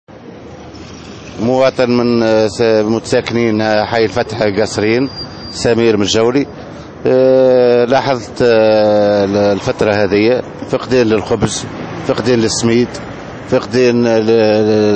Les habitants de Kasserine sont intervenus aujourd’hui au micro de Tunisie Numérique pour exprimer leur colère face à la pénurie de certains matières premières dont la semoule et la farine.